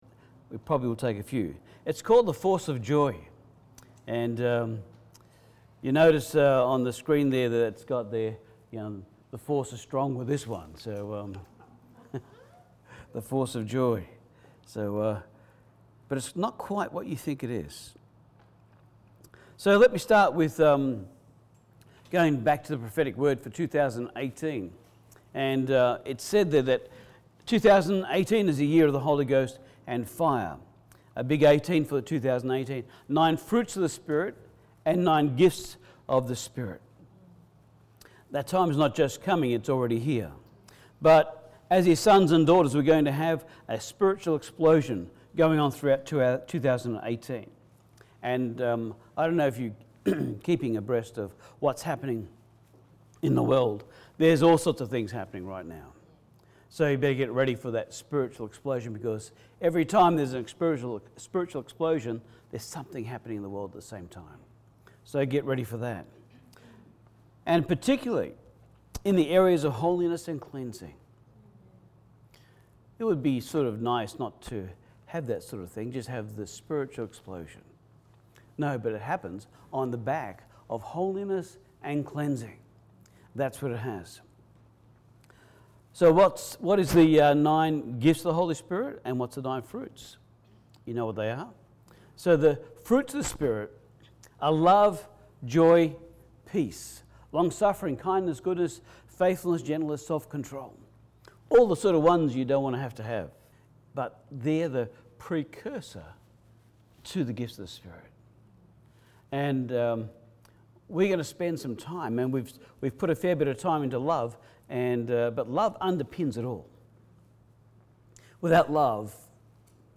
The Force of Joy Service Type: Sunday Service Most Christian’s don’t really believe that God love them.